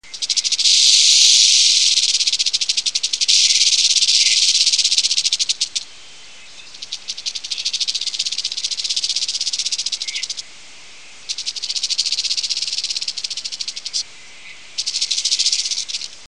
На этой странице собраны разнообразные звуки змей: от устрашающего шипения до угрожающих вибраций хвоста.
Гремучая змея дрожит хвостом